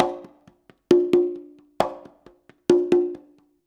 133CONGA01-L.wav